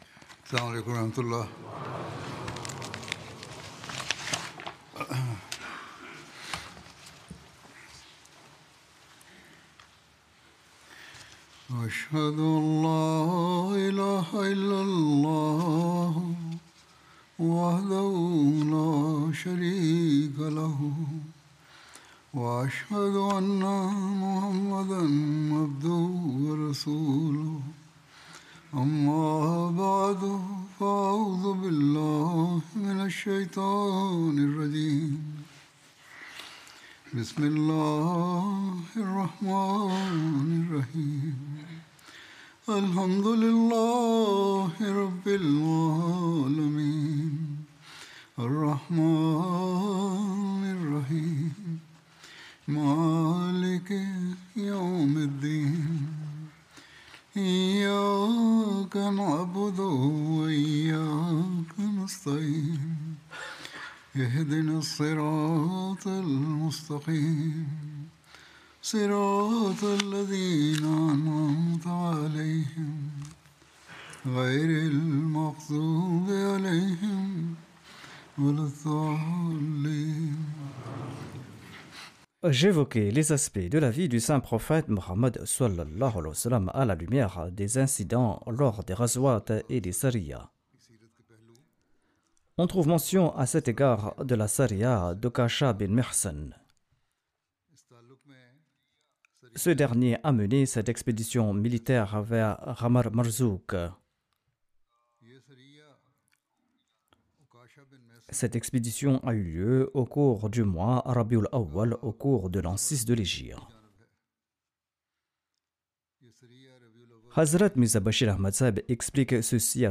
French Translation of Friday Sermon delivered by Khalifatul Masih